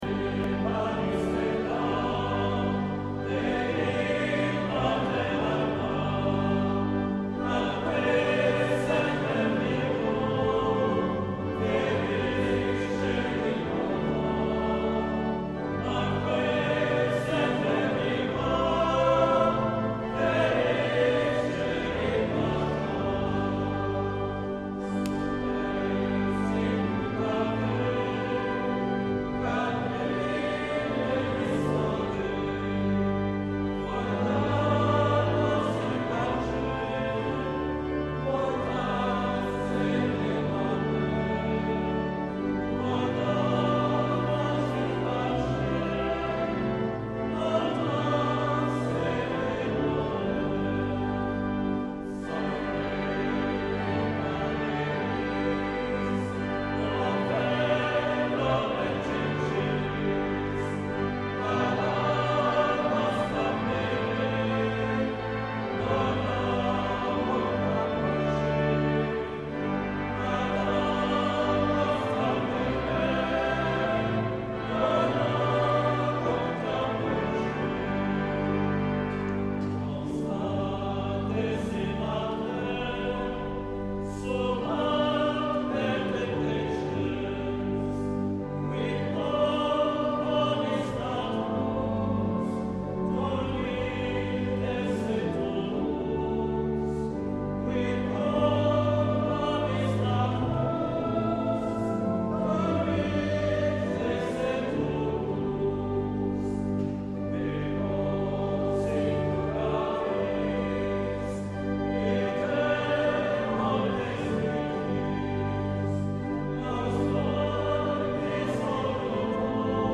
Chaque dimanche et fête messe tridentine à 9h